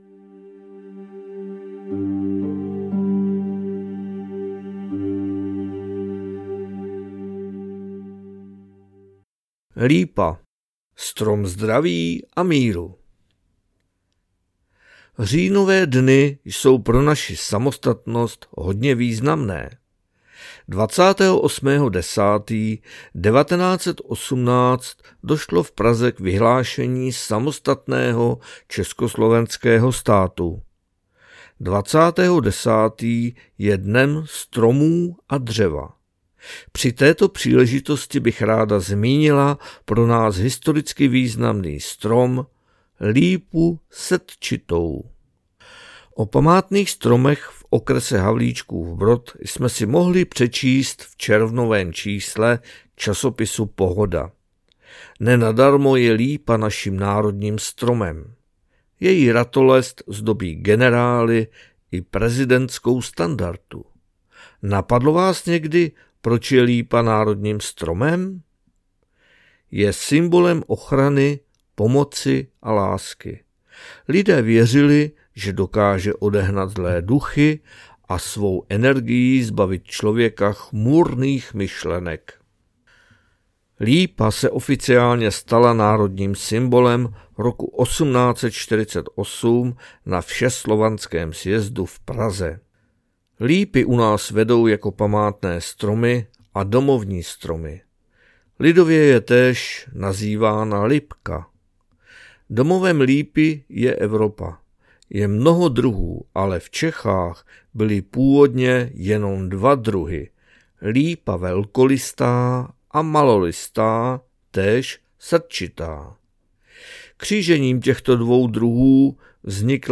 SONS ČR - PAPRSEK ŘÍJEN 2024 NAČTENÝ